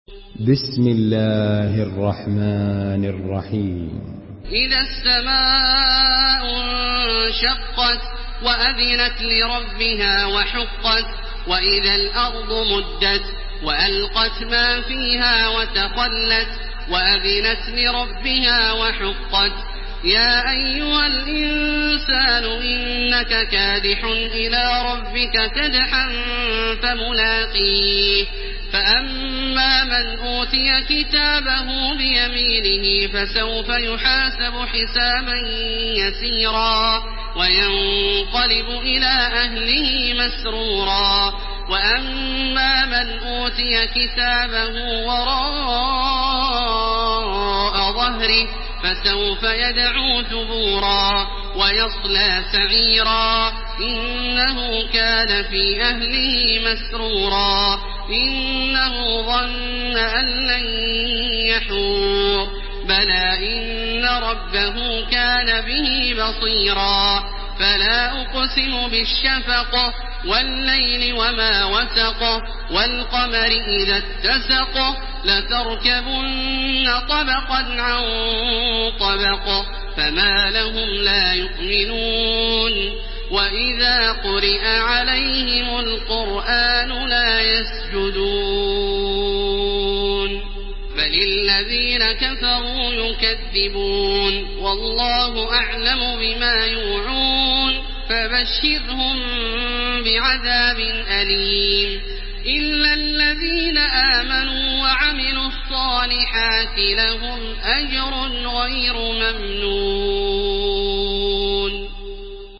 Surah Al-Inshiqaq MP3 by Makkah Taraweeh 1430 in Hafs An Asim narration.
Murattal Hafs An Asim